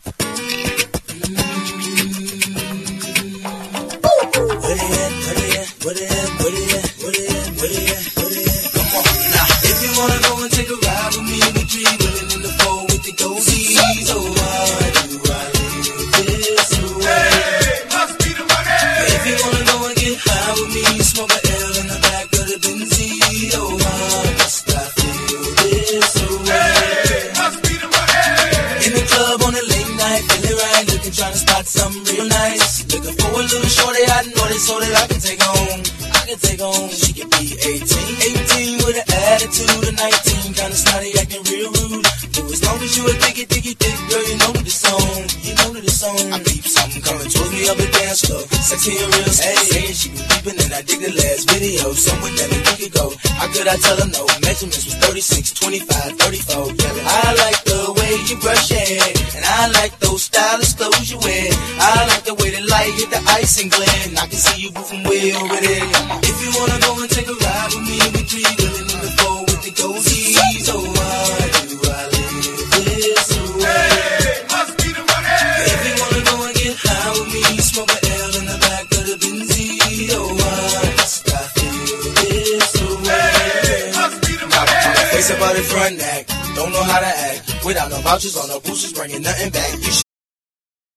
ALBUM VERSION